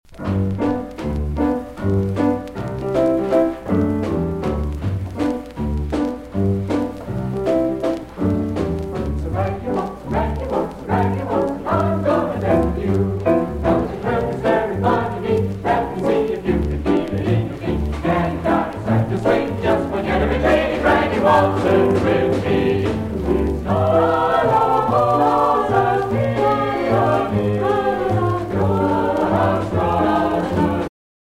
Performer Jazz Ensemble; Jazz Chorus
saxophone
trumpet
rhythm section
Jazz vocals